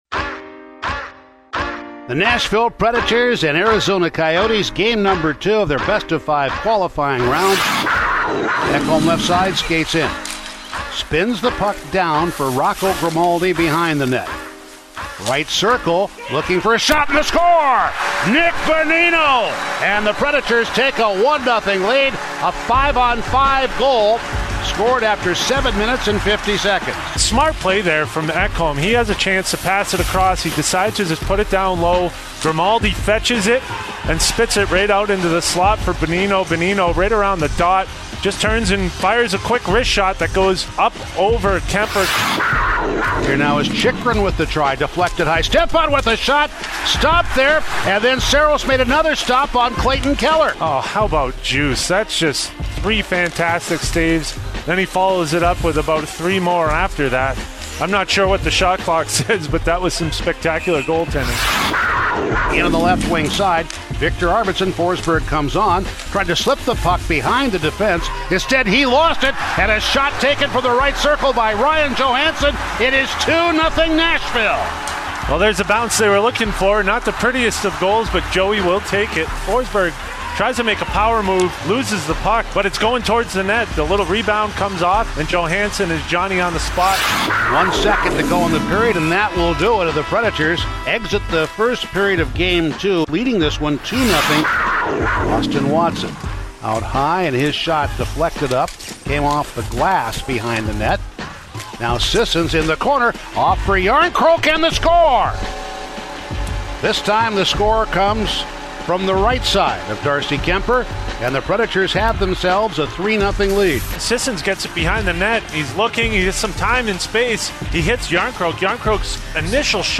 Full radio highlights from the Preds 4-2 win over the Coyotes to even their Qualifying Round series on August 4, 2020.